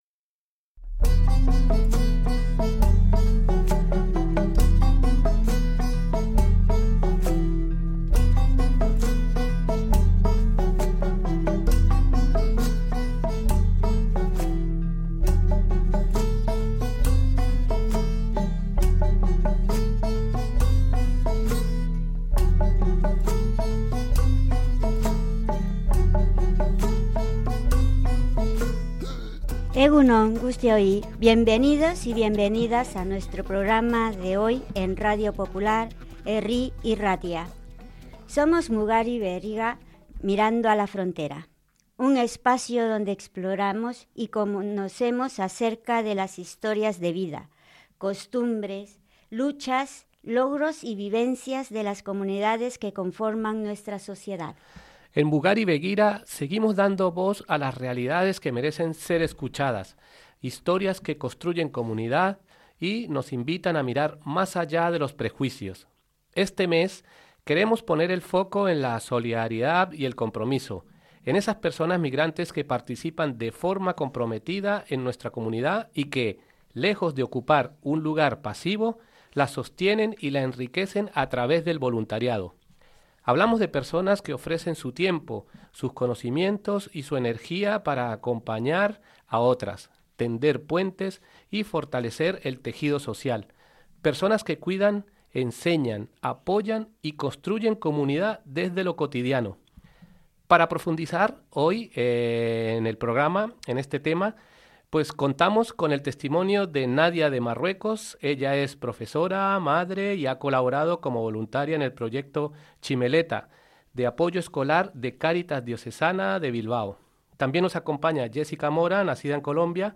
Dos pertsonas voluntarias nos cuentan su experiencia y nos animan a ayudar al prójimo